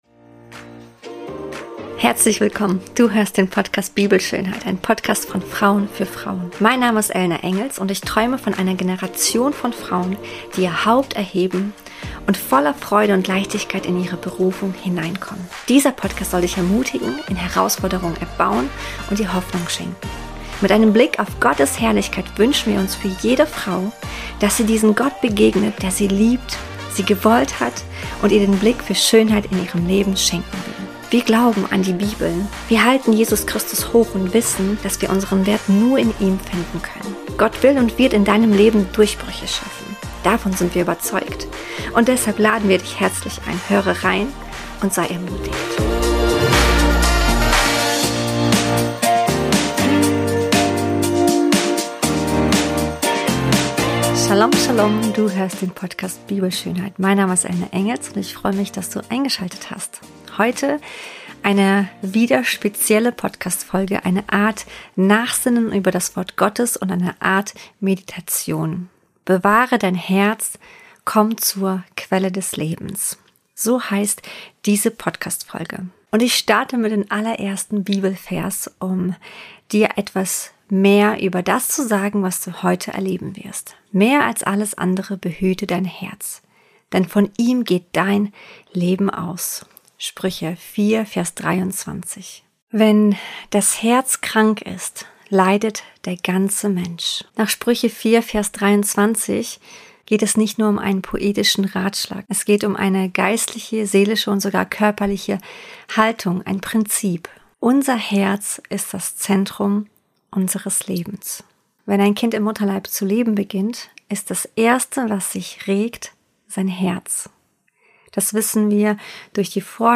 Meditation: Bewahre dein Herz – komm zur Quelle des Lebens ~ BIBELSCHÖNHEIT Podcast